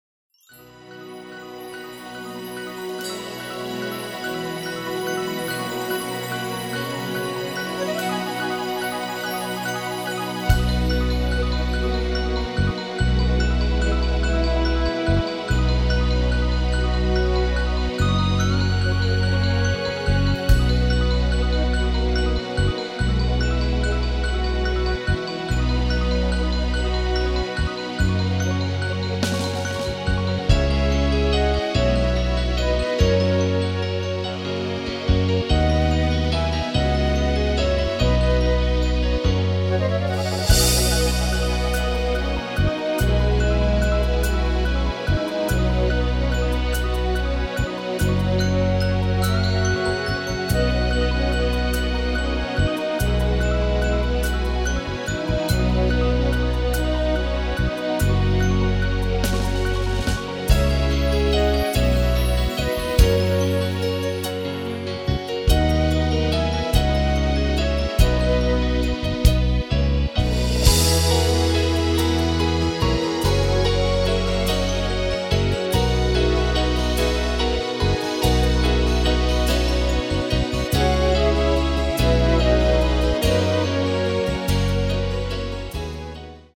• Tonart: Bb – Dur, H Dur
• Art: Bandplayback
• Das Instrumental beinhaltet keine Leadstimme
Lediglich die Demos sind mit einem Fade-In/Out versehen.
Klavier / Streicher